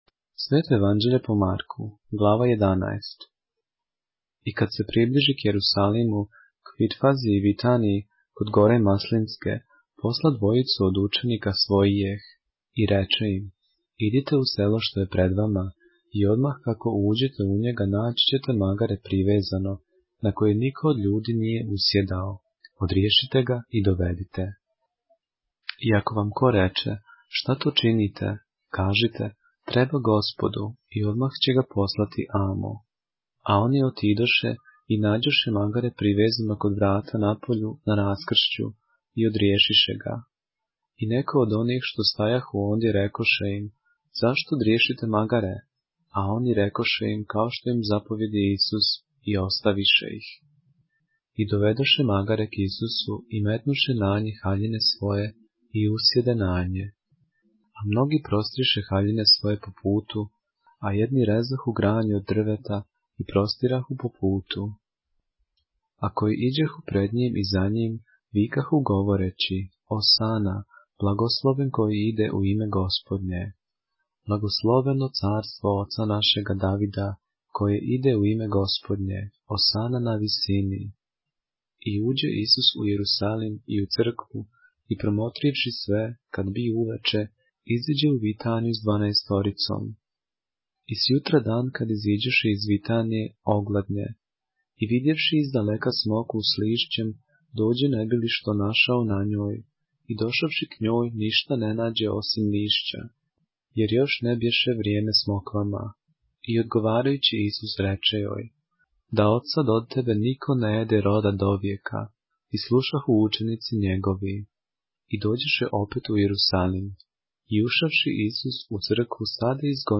поглавље српске Библије - са аудио нарације - Mark, chapter 11 of the Holy Bible in the Serbian language